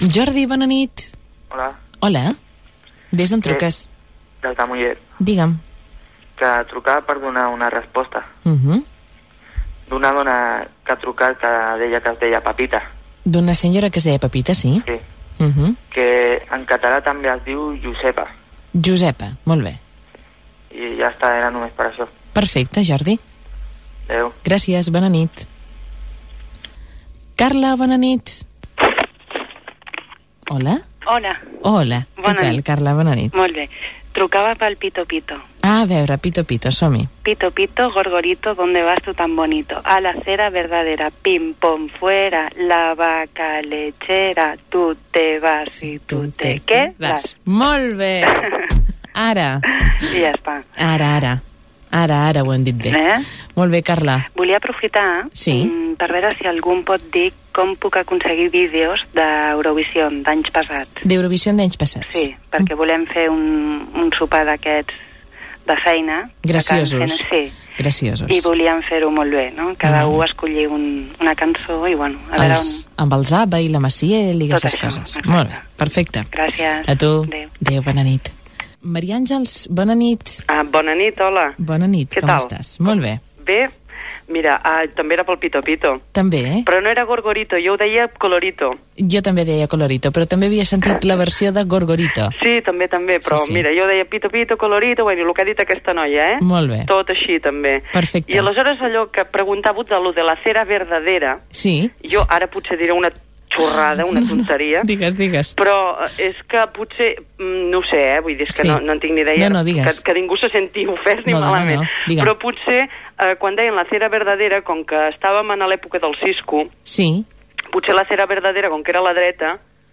Sintonia del programa, presentació, identificació del programa, equip, i dues primeres trucades consultant dos temes, un relacionat amb les cerveses sense alcohol.
Respostes de l'audiència a un parell de preguntes, sintonia i comiat del programa.